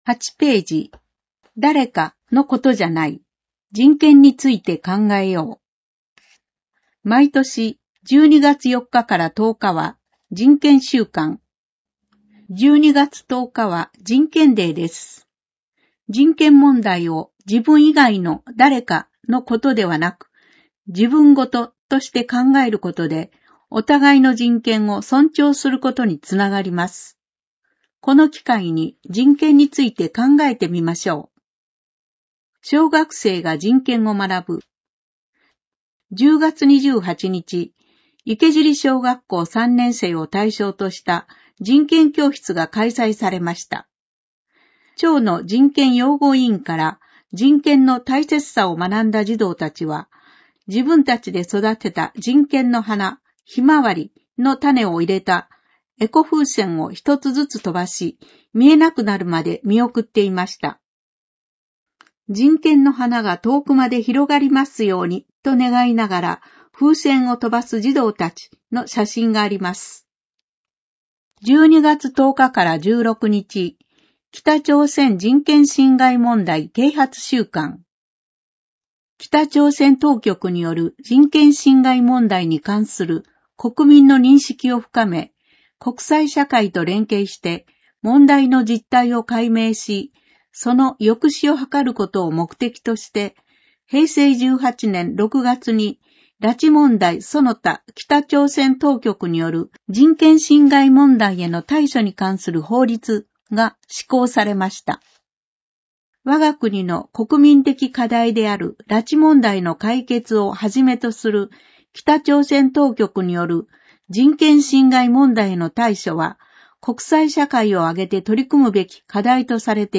『広報かわさき』を音訳している川崎町朗読ボランティア「ひまわり」の作成した音声データを掲載しています。